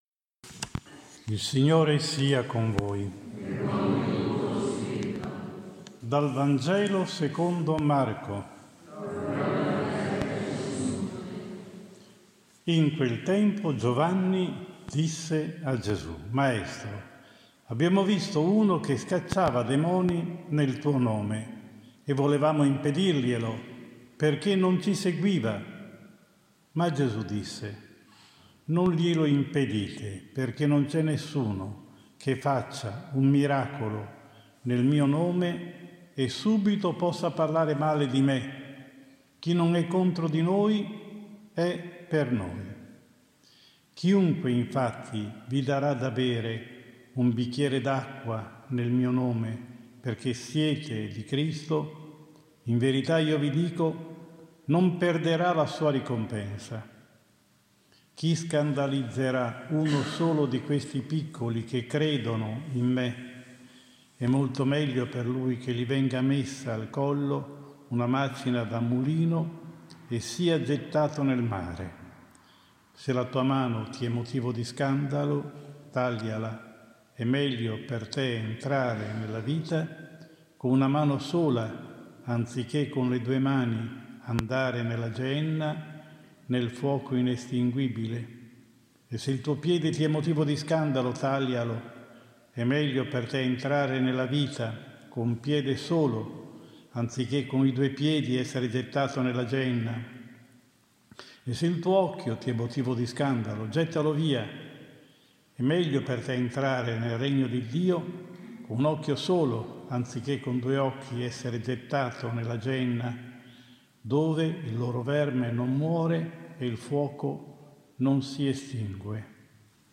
Ultimo martedì della Stella in preparazione alla festa della Madonna dell’Apparizione – Omelia alla Messa